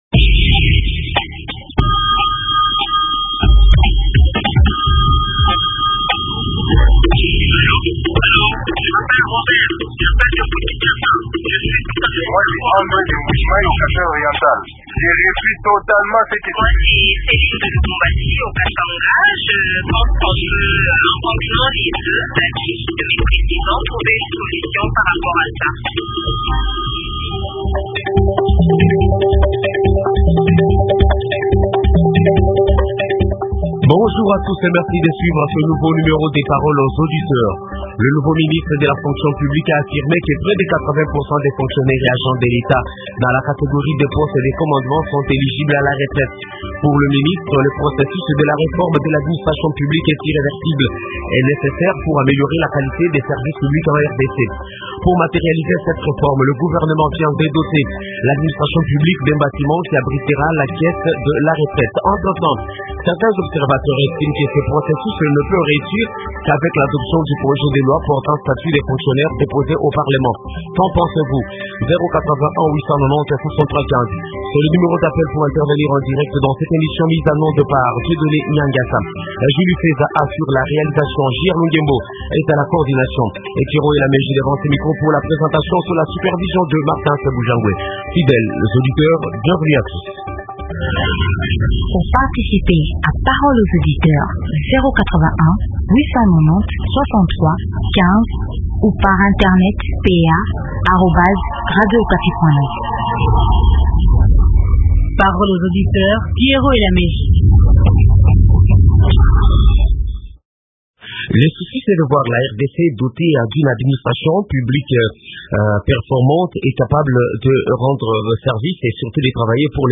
Invité: Pascal Isumbisho, ministre de la fonction publique.